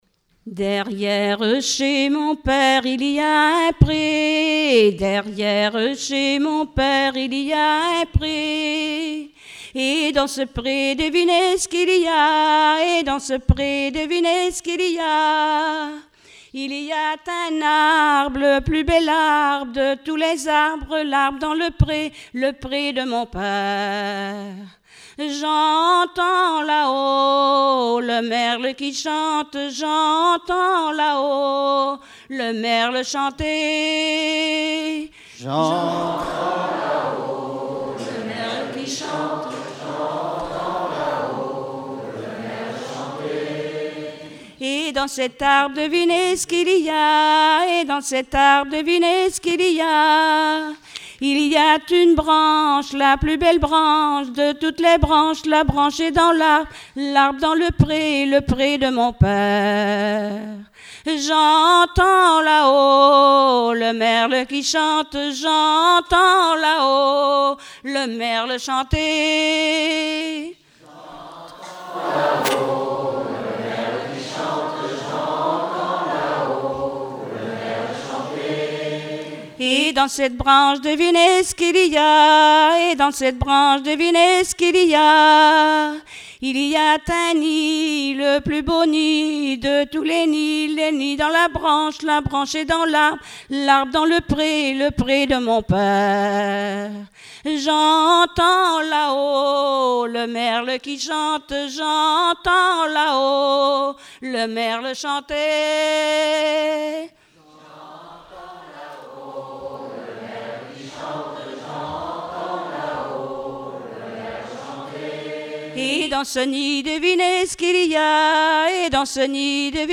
Genre énumérative
Festival du chant traditionnel - 31 chanteurs des cantons de Vendée
Pièce musicale inédite